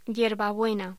Locución: Yerbabuena
voz